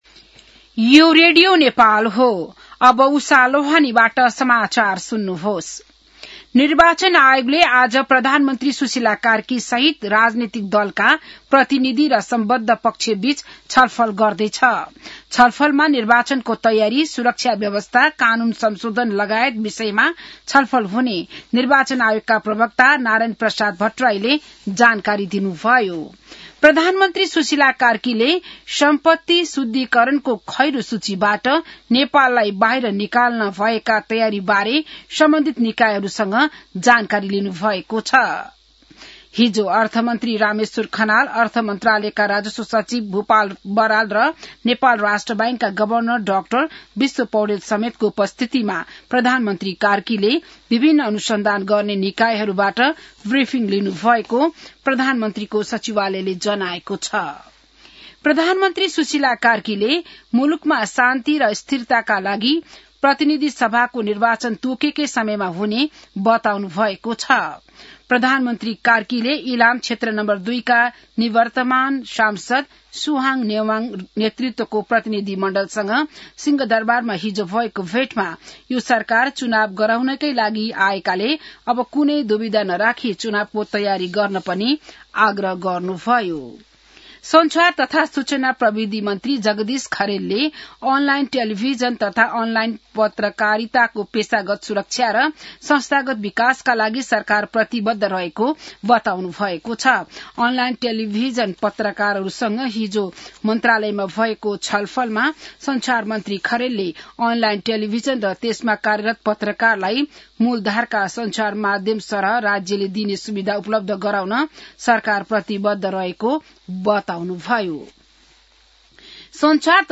An online outlet of Nepal's national radio broadcaster
बिहान १० बजेको नेपाली समाचार : १८ पुष , २०२६